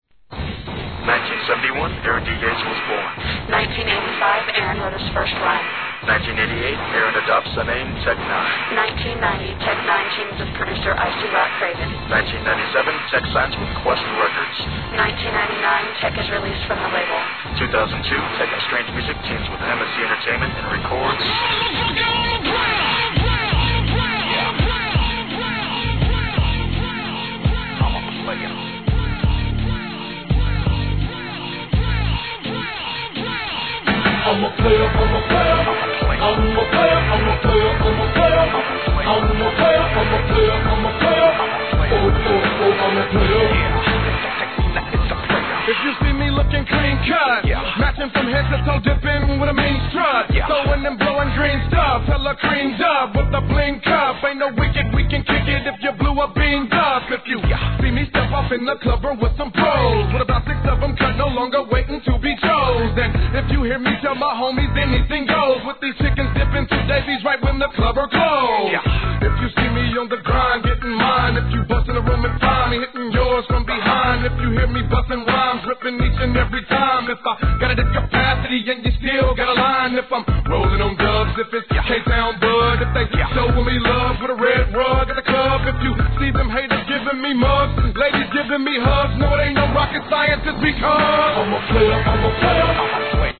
G-RAP/WEST COAST/SOUTH
ダイナミックなストリングスが聴いた激しく打ち込まれるバウンス！！